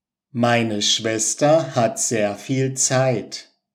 ไม-เน่อะ ชเว็ส-เทอร์ ฮัท แซร์ ฟีล ไซ(ท)